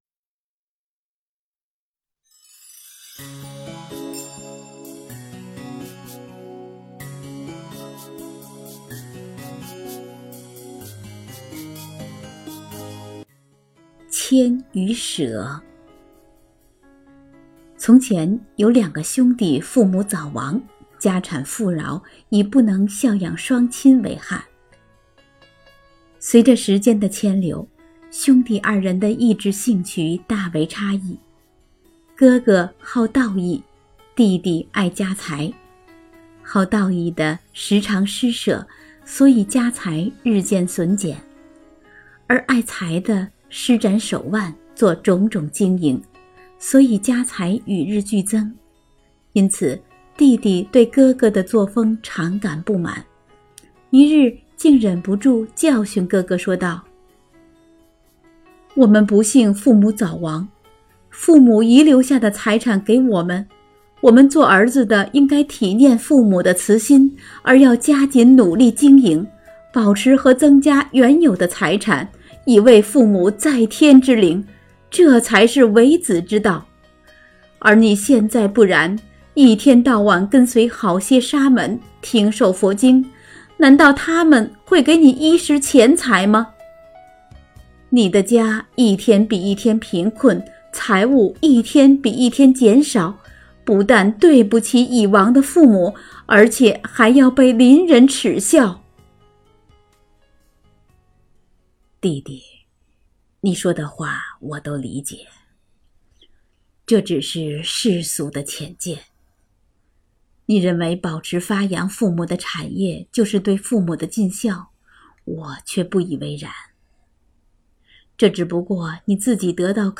佛音 诵经 佛教音乐 返回列表 上一篇： 09.